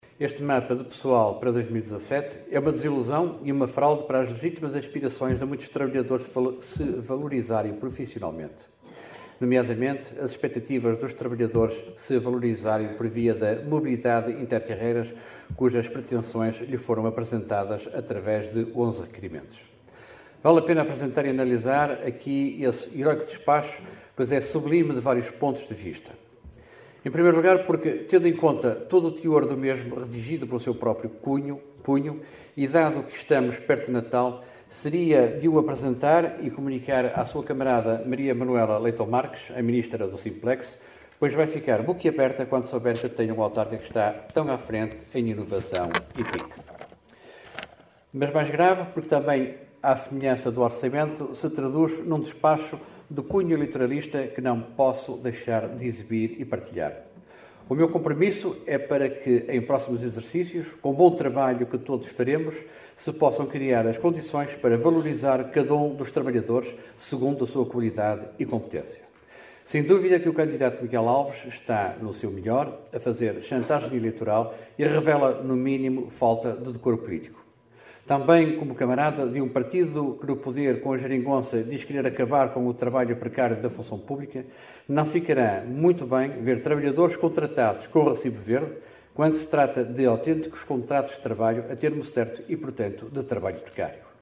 Este foi mais um dos assuntos discutidos na Assembleia Municipal da passada sexta-feira em que o PSD, pela voz de Rui Taxa, acusou o presidente da Câmara de Caminha “de não ter qualquer orientação e estratégia” para os recursos humanos do município.